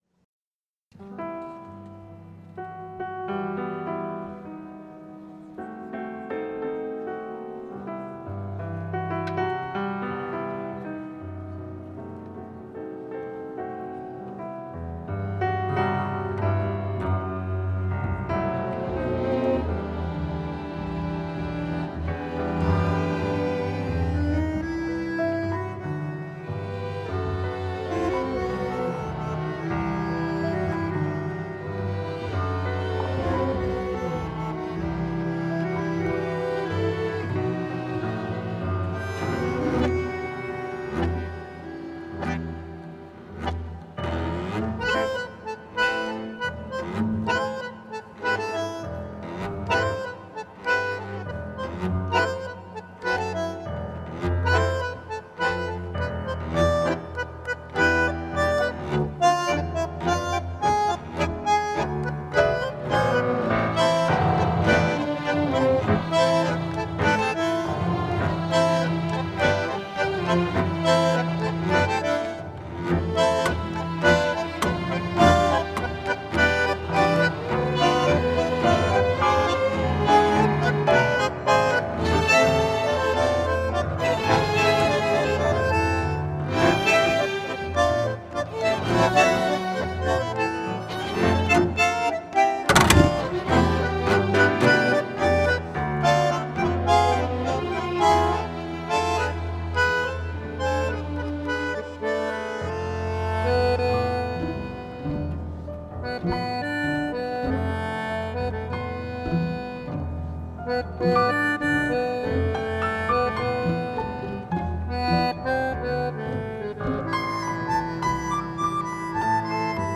Audiofragmenten optreden tijdens Festival Tango a la carte – 18 Juni 2016 in de Grote Kerk te Alkmaar; “Nostalcolico”en “Al Compas Del Corazon”.
Optreden Tango Orkest Amsterdam in Alkmaar